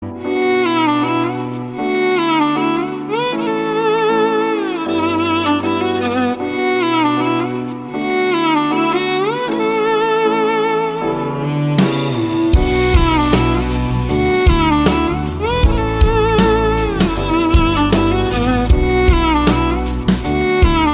Category Instrumental